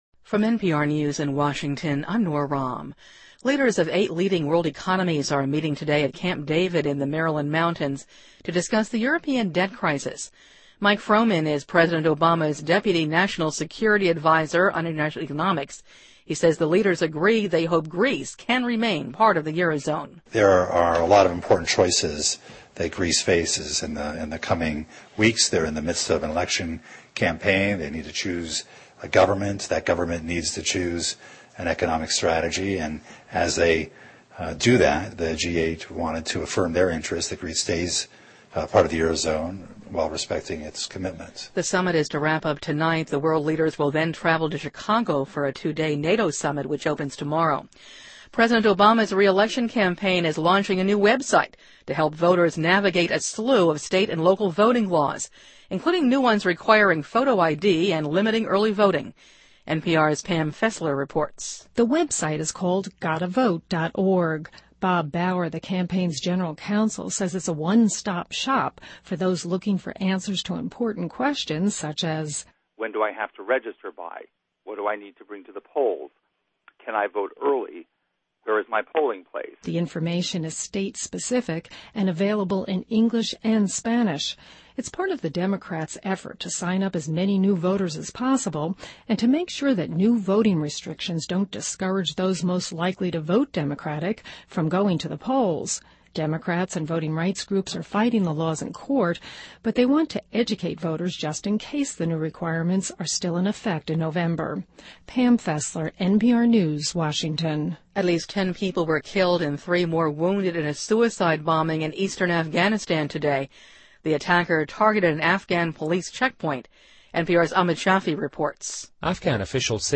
NPR News,G8峰会召开领导人希望希腊仍然留在欧元区